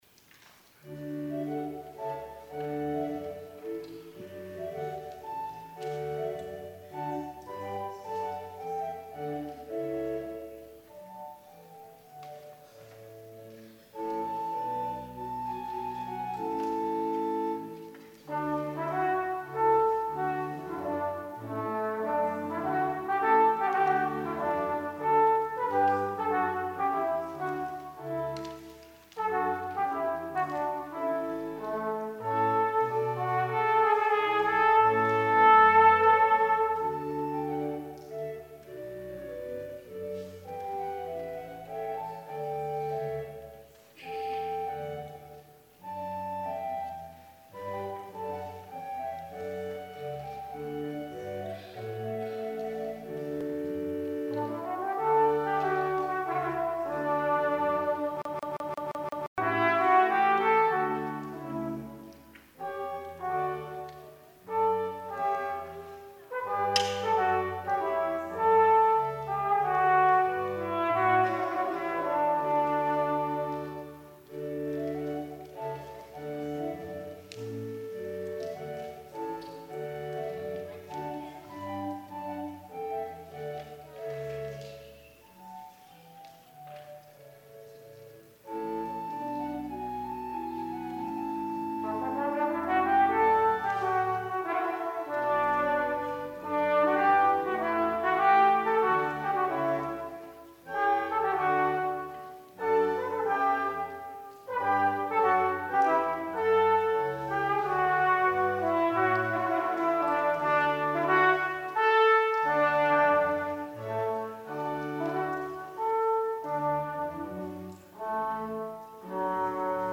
trombone
organ